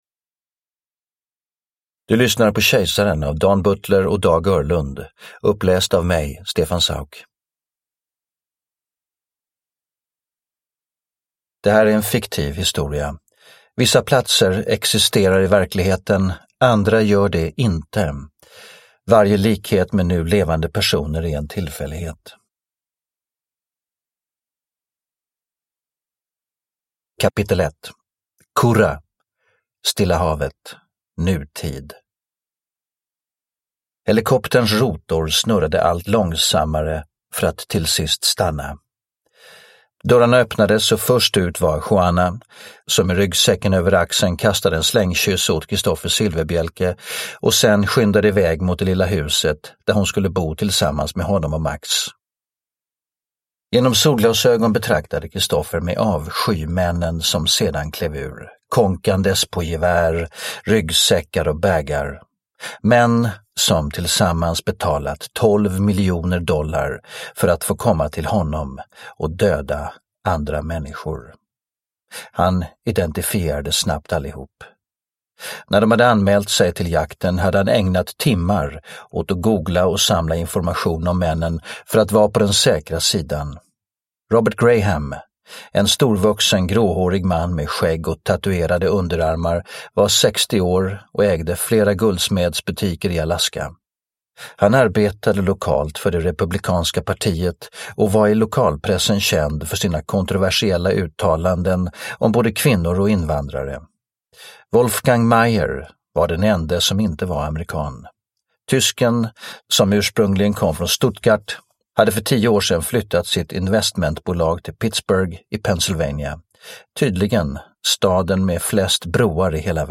Silfverbielke 9 Kejsaren / Ljudbok